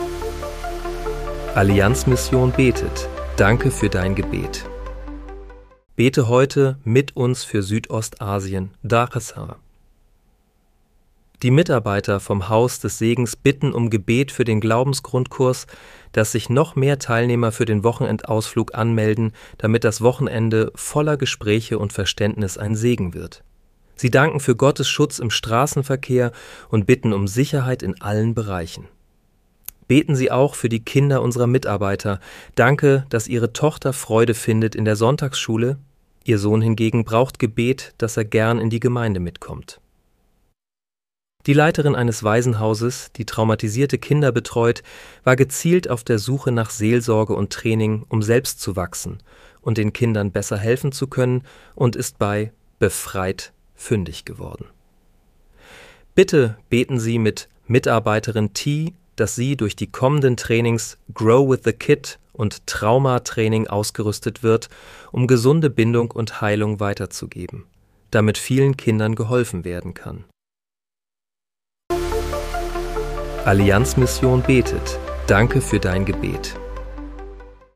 Bete am 20. April 2026 mit uns für Südostasien. (KI-generiert mit